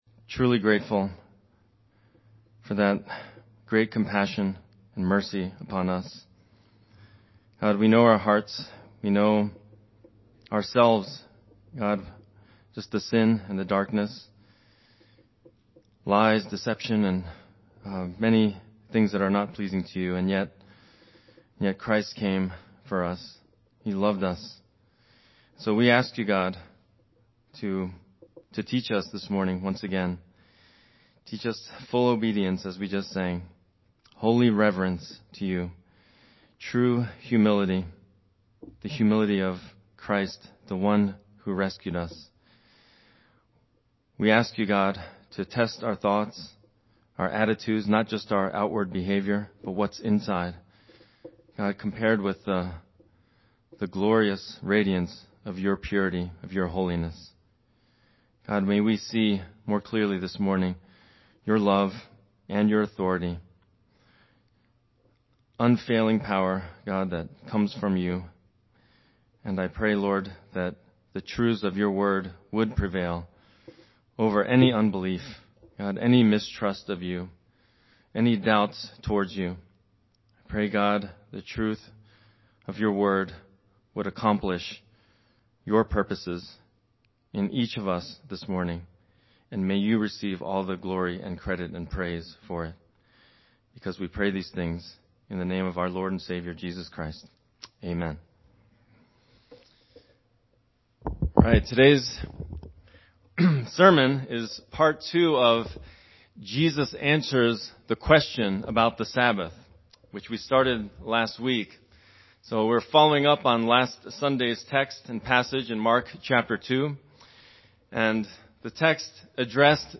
Sermon Theme/Outline: Jesus demonstrates His lordship over the sabbath and teaches what God’s laws are all about